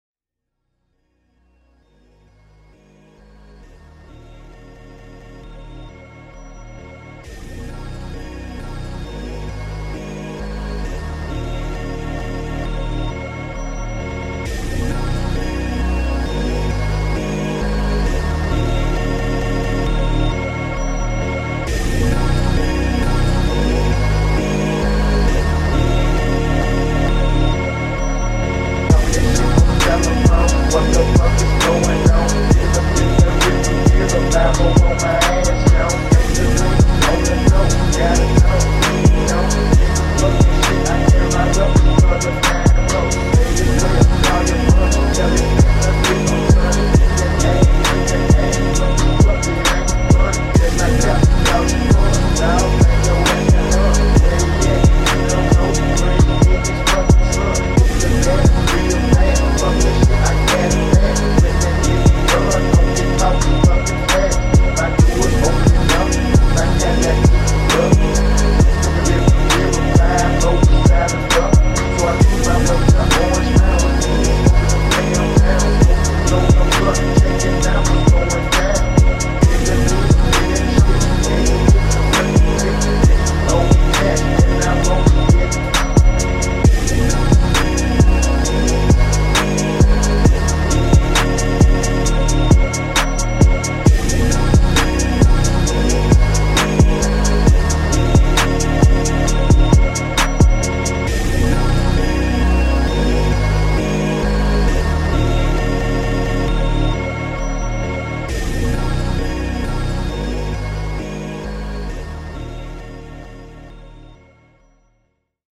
throwback to phonk vocals. take two with fl studio.
phonk